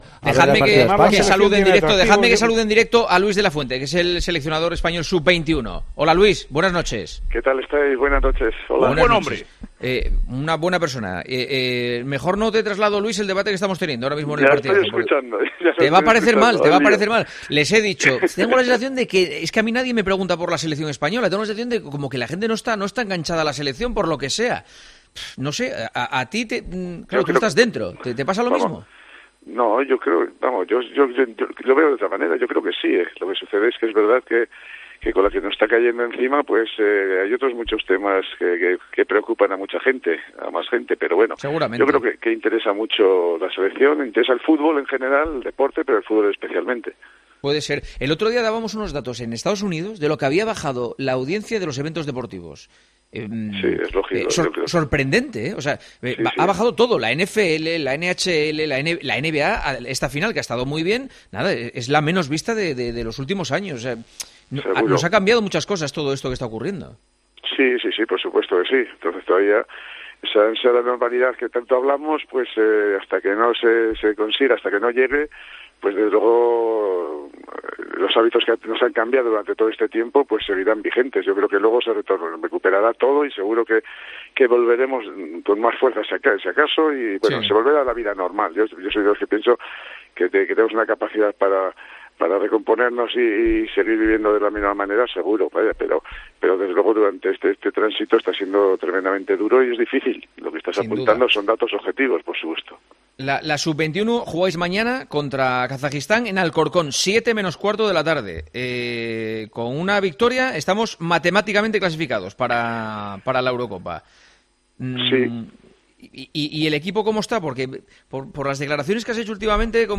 AUDIO: El seleccionador sub 21 habla en El Partidazo de COPE de la situación del equipo y de los problemas que ha pasado por el coronavirus.